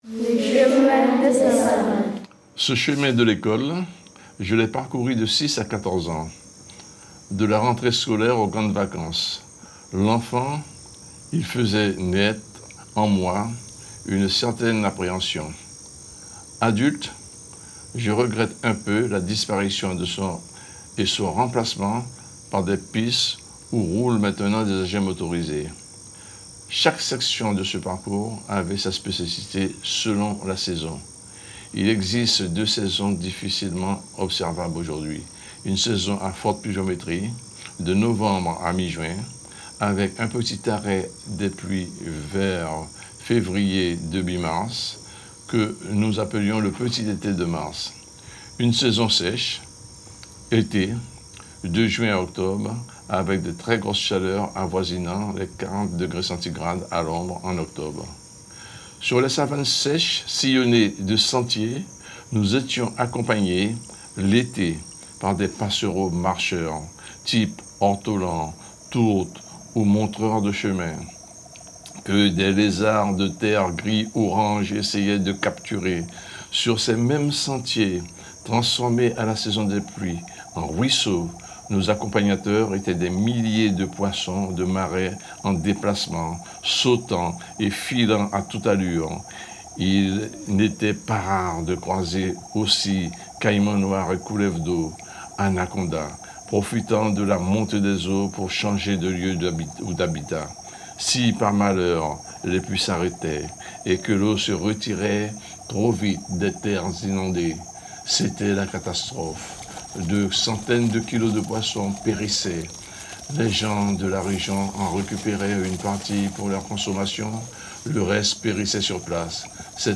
Le conte (écoute en voiture):